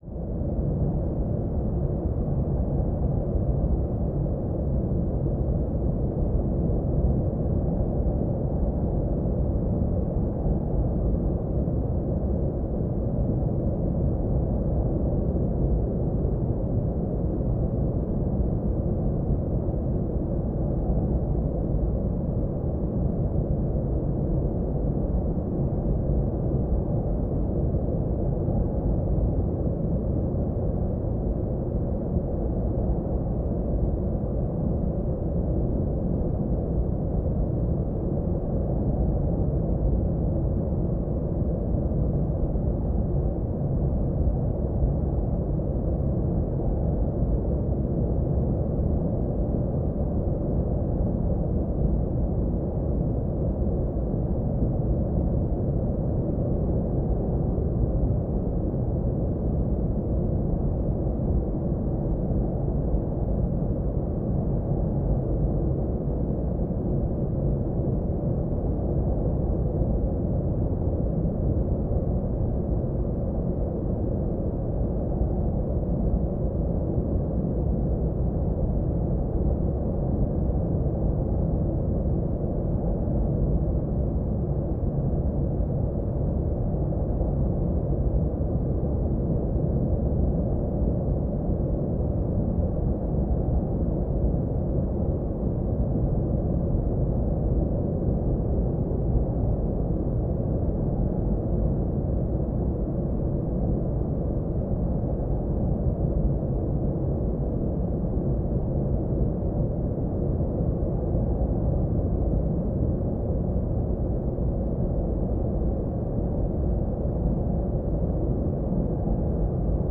01 - Bruit brun relaxant.flac